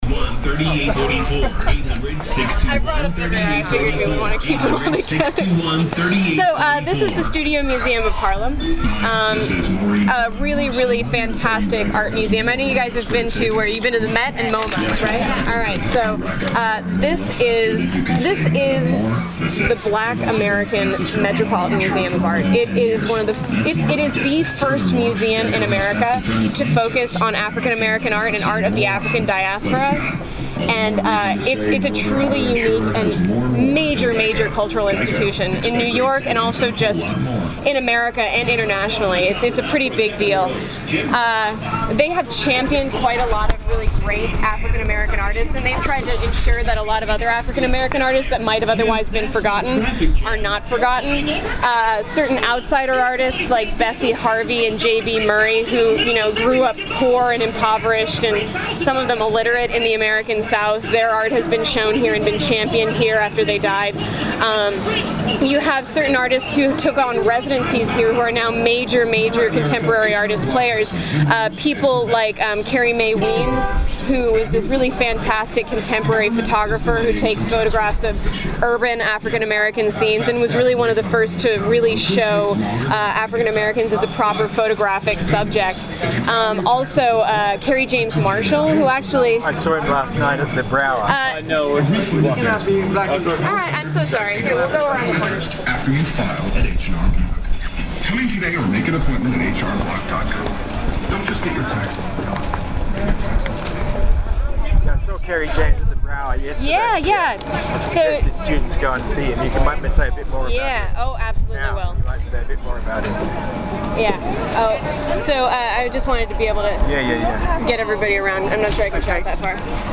our expert historian guide